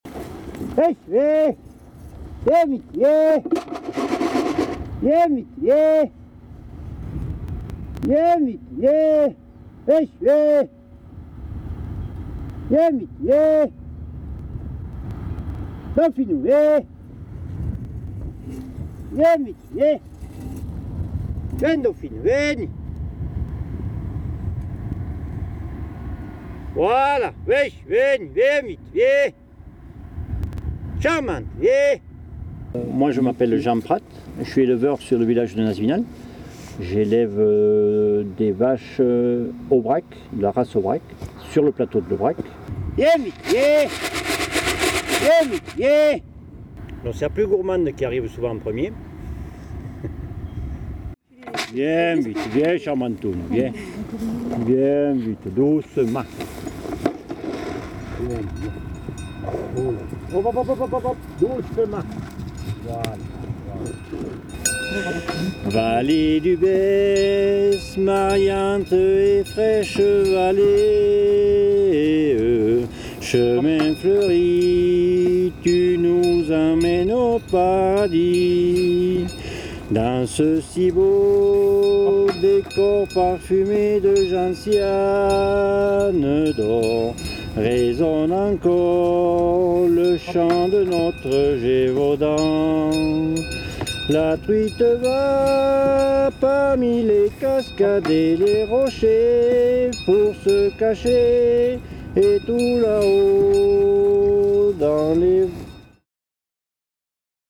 Les sonoportraits
chant du plateau de l’Aubrac en français